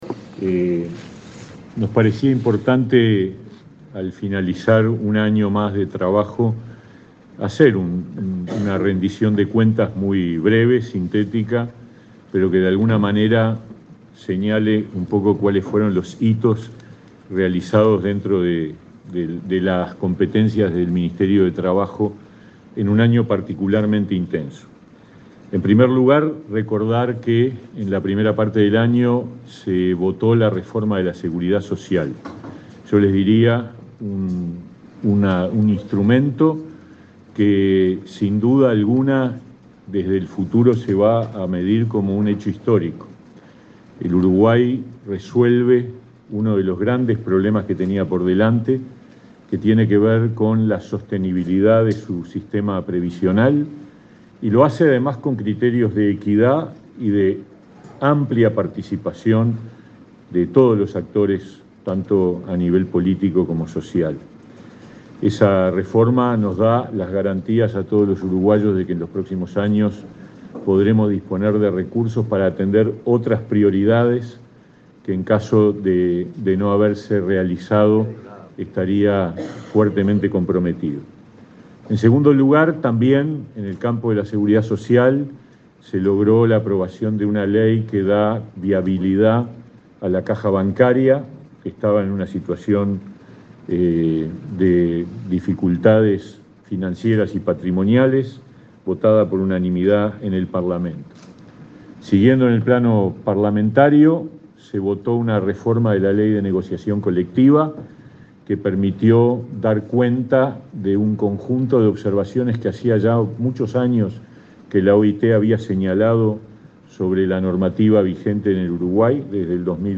Conferencia del ministro de Trabajo, Pablo Mieres
El ministro de Trabajo, Pablo Mieres, brindó una conferencia de prensa para informar sobre el balance de 2023 en materia de negociación colectiva,